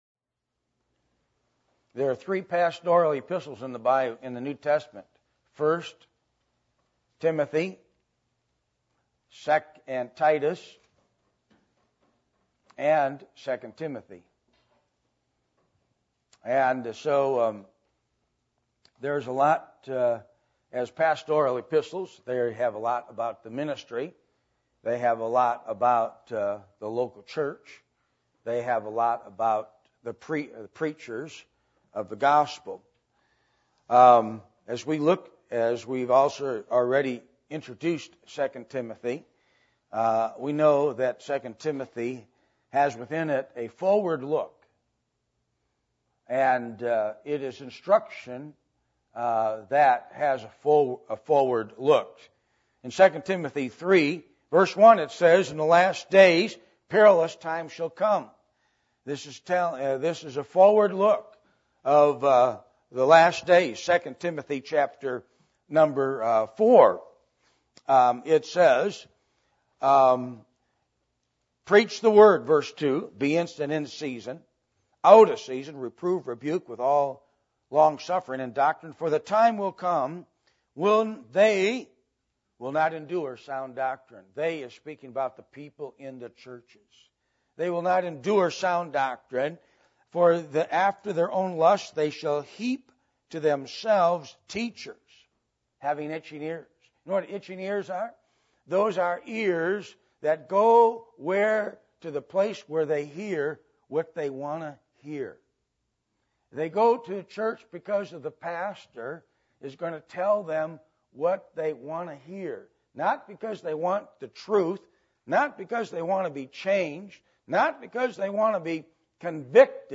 Passage: 2 Timothy 2:2 Service Type: Sunday Morning %todo_render% « The Attributes Of God